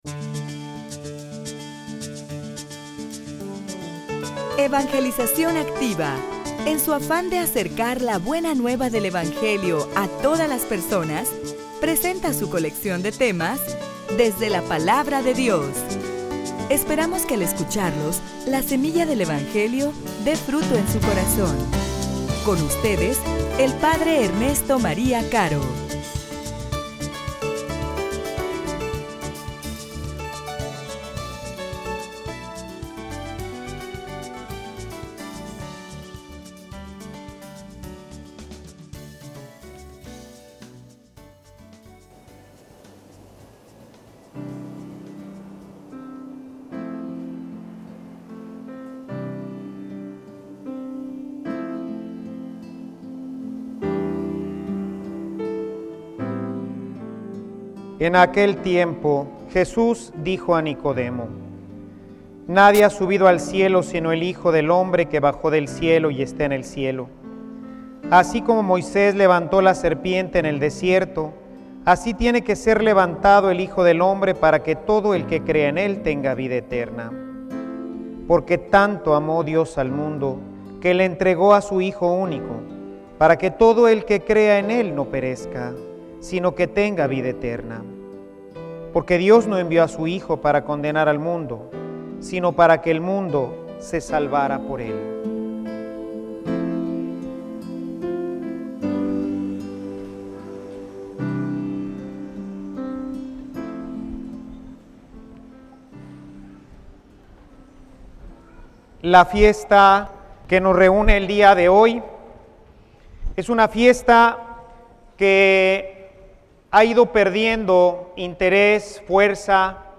homilia_La_cruz_nos_purifica.mp3